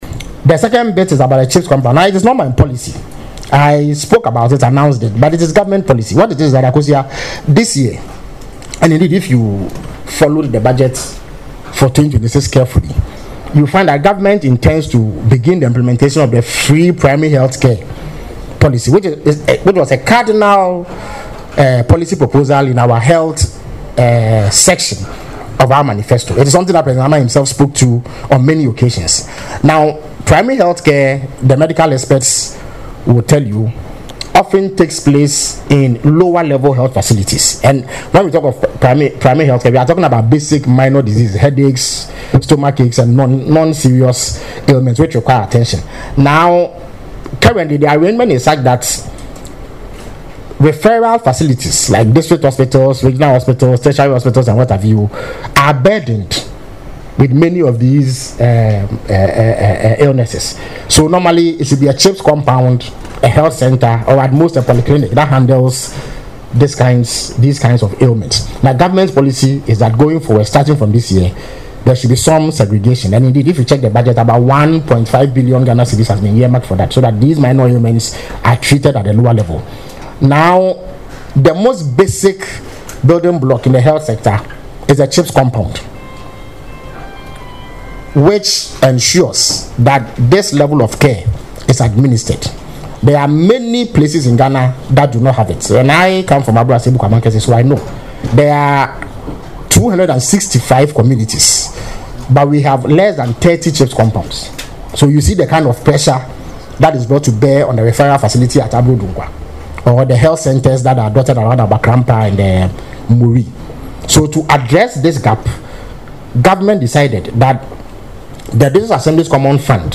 Addressing the media on Wednesday, January 14, during the Government Accountability Series, Mr. Kwakye Ofosu noted that the directive for MMDAs to construct at least two CHPS compounds is a critical step toward the rollout of the government’s Free Primary Healthcare Policy.
Listen to Felix Kwakye Ofosu speak on the impact of the DACF direct transfers in the audio below.